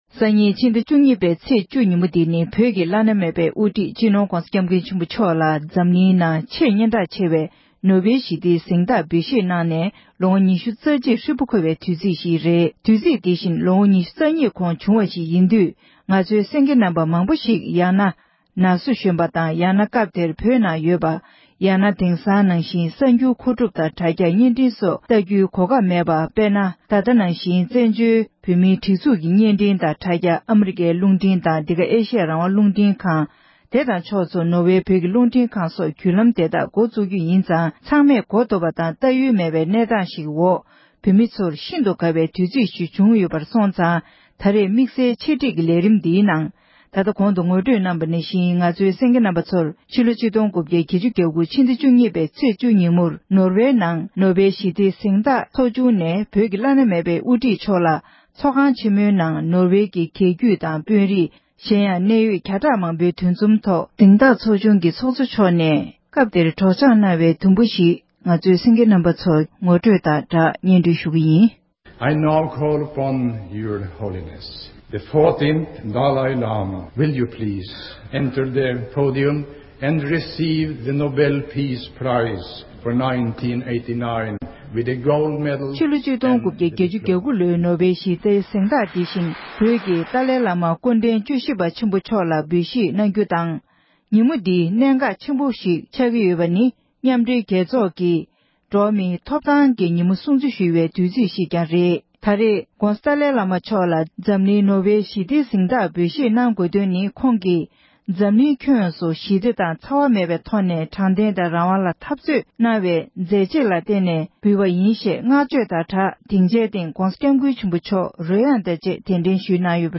༡༩༨༩བསྩལ་བའི་བཀའ་སློབ།
༸གོང་ས་མཆོག་ནས་༡༩༨༩ལོར་ནོ་བེ་ཞི་བདེའི་གཟེངས་རྟགས་འབུལ་བཞེས་གནང་སྐབས་བསྩལ་བའི་བཀའ་སློབ།